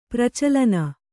♪ pracalana